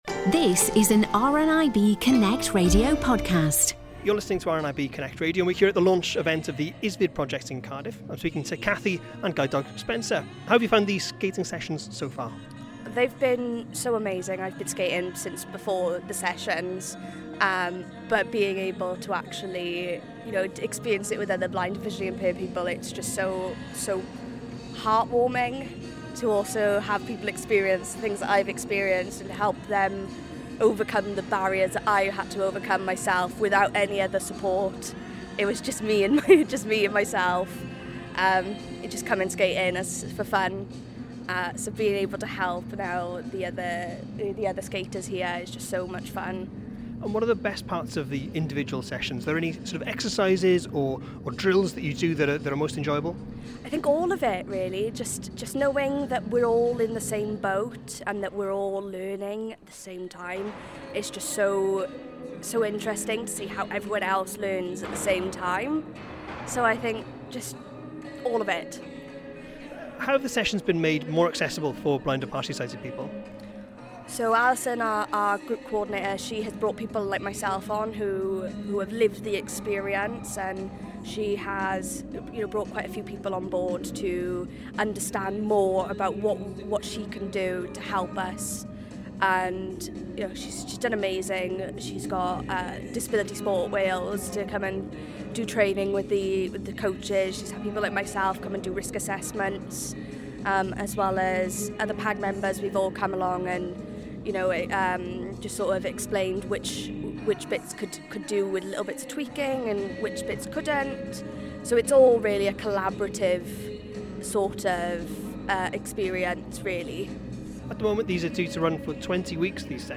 spoke to one of the participants in Cardiff Bay to find out more.